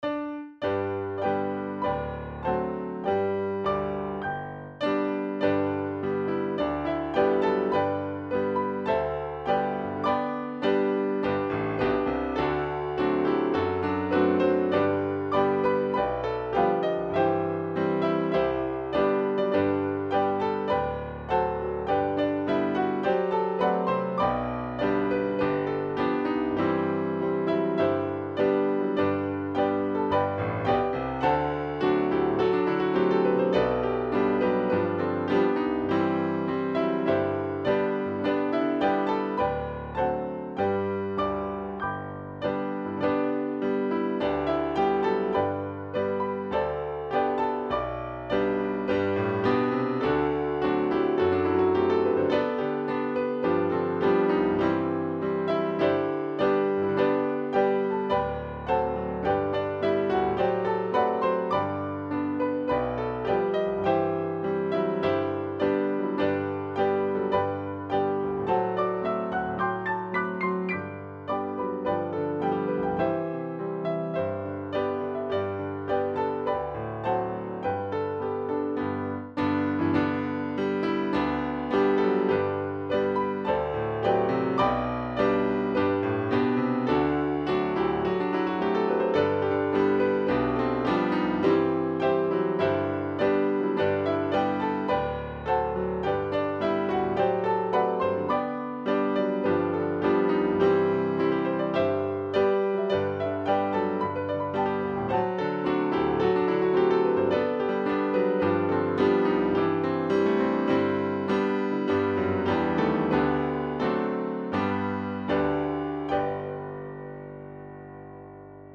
Key: G